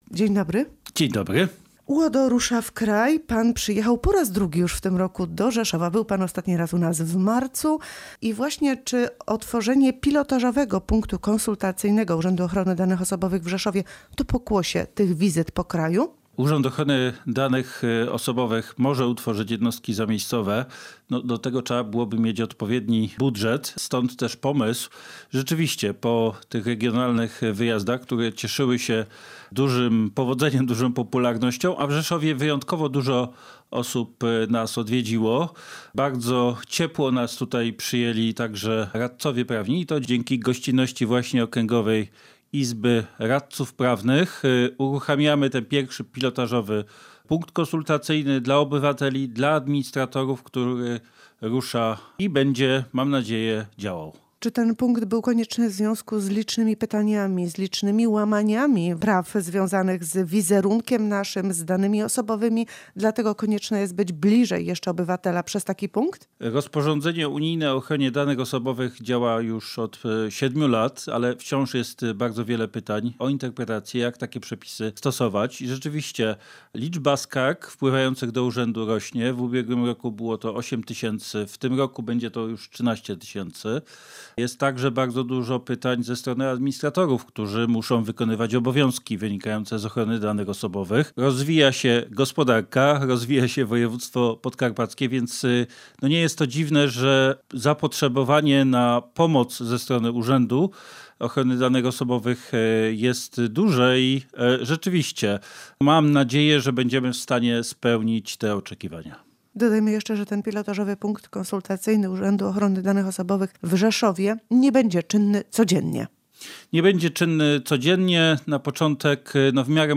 Jak poinformował w audycji Kalejdoskop prezes UODO Mirosław Wróblewski, ich liczba zwiększyła się z 8 do 13 tysięcy.